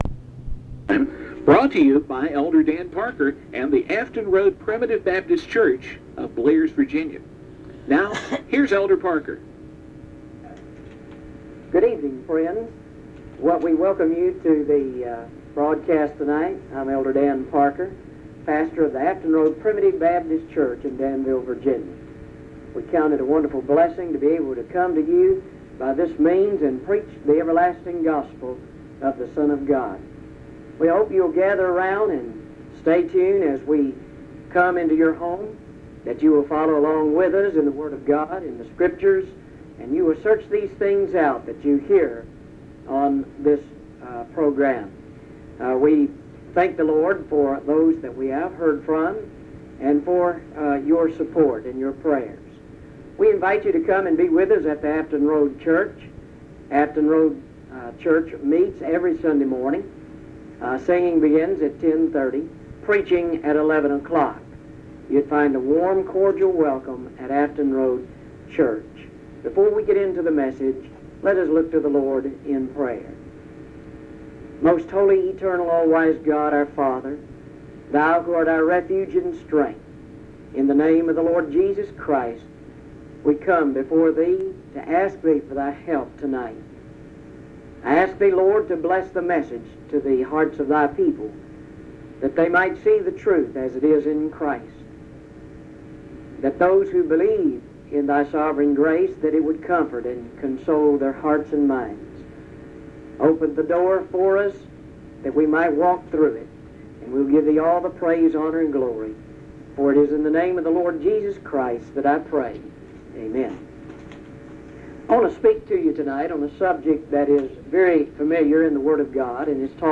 Psalm 89:30-36; Recording from a broadcast